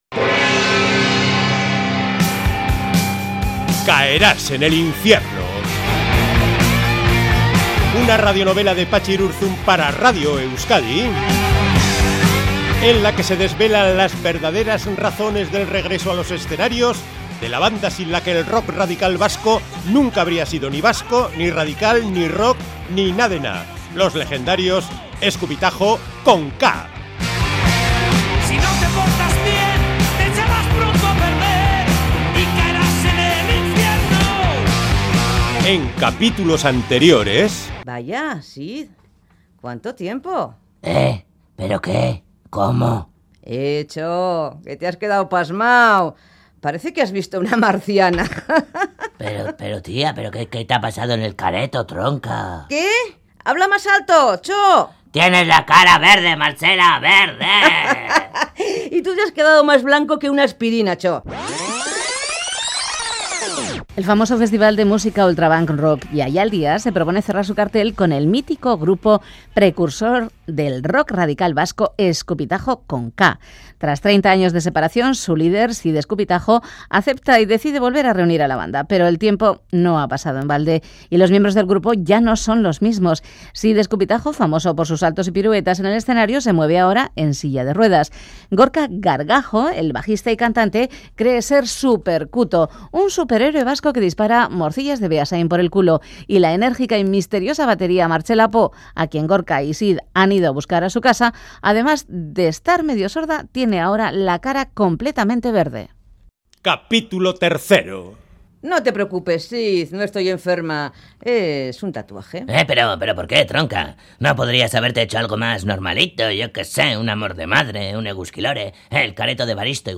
Audio: CAERÁS EN EL INFIERNO es una radio novela escrita por Patxi Irurzun para Radio Euskadi. Un grupo punk tratando de volver a la música.